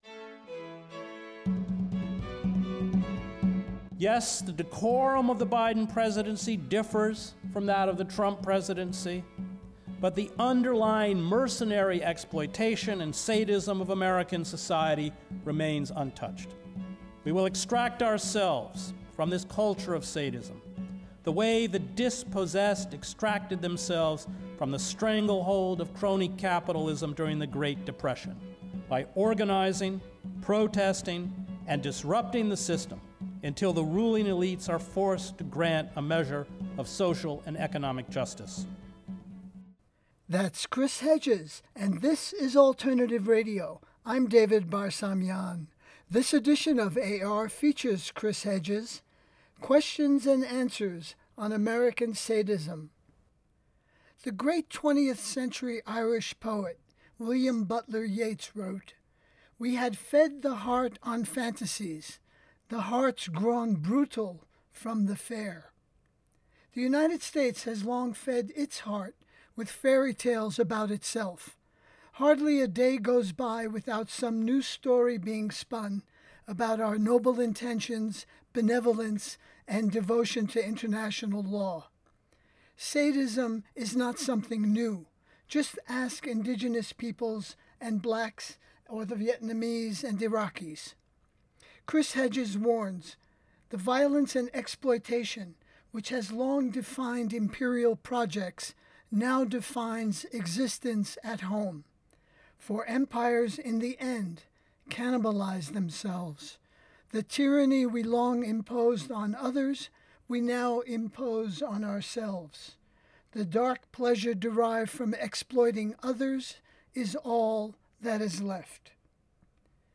Chris Hedges – Q&A on American Sadism
ChrisHedges-QandAonAmericanSadism_pacNCRA.mp3